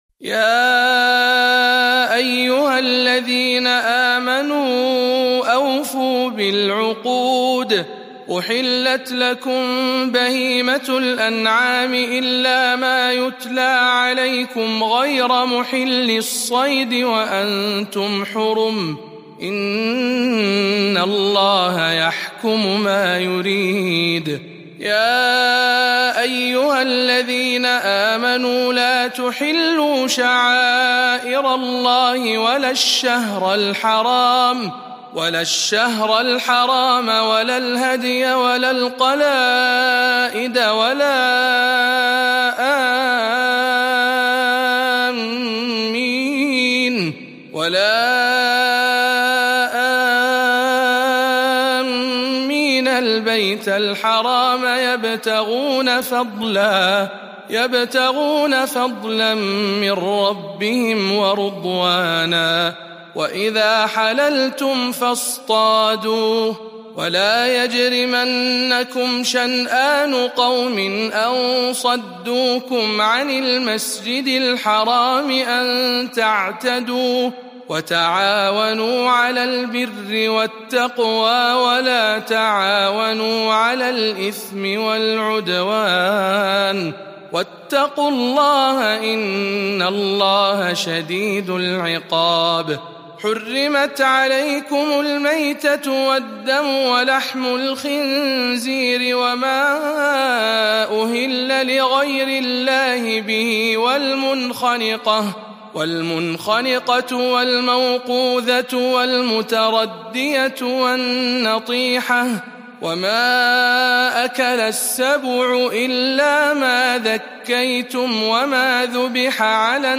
005. سورة المائدة برواية شعبة عن عاصم